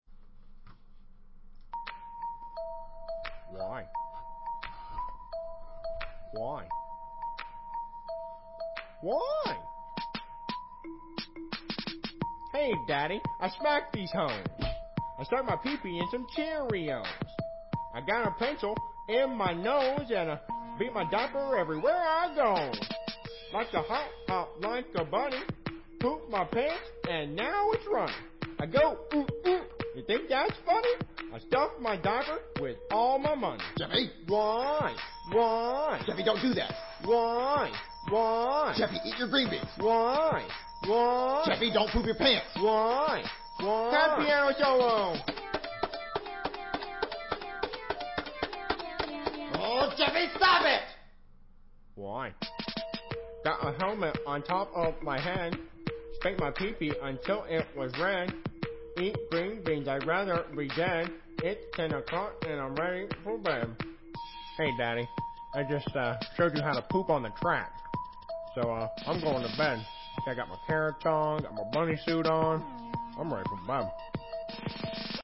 new-jeffy-rap-reduced.mp3